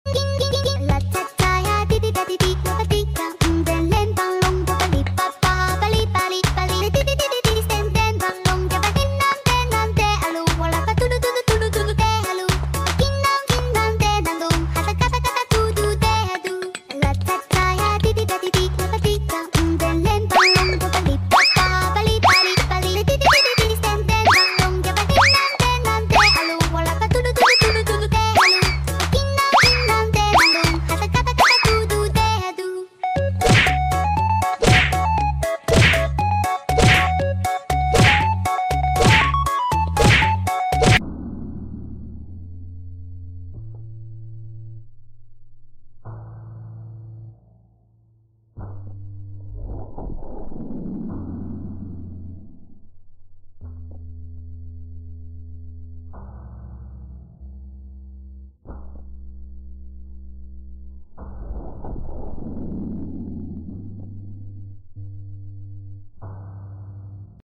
Letter E sand cake satisfying sound effects free download
Relax and enjoy this oddly satisfying sand cake in the shape of the letter E ✨ Perfect ASMR vibes to calm your mind and keep you entertained 🎂🧡